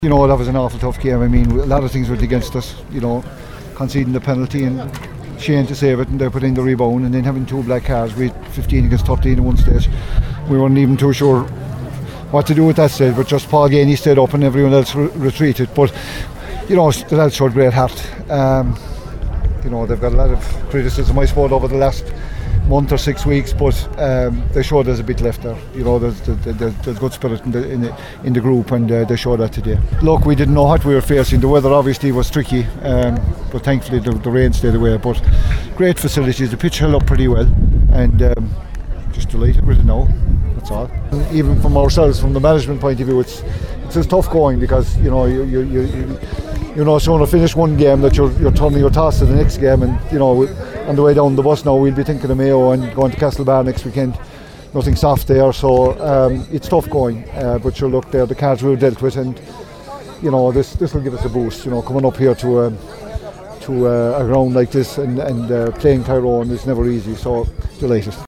Kerry manager Jack O’Connor also gave his thoughts to the media…